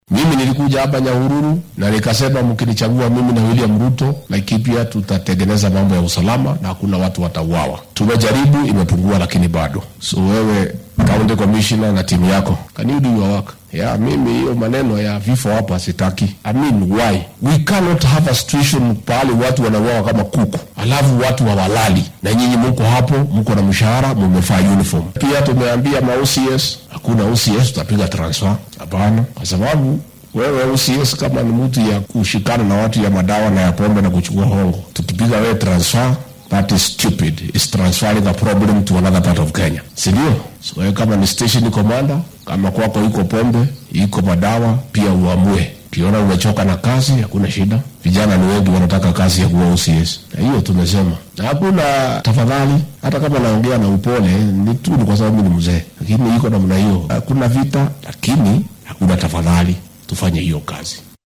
Arrintan ayuu ka sheegay magaalada Nyahururu ee ismaamulka Laikipia.